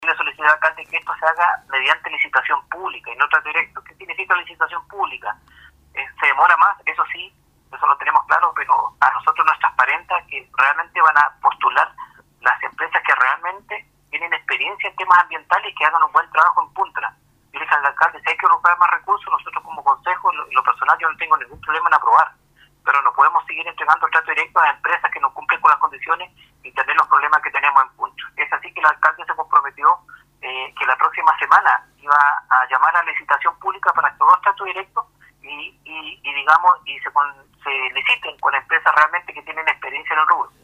Al respecto conversamos con el concejal Alex Muñoz, quien señaló que no se pudo comprobar la experiencia de estas empresas en ejecuciones en rellenos sanitarios.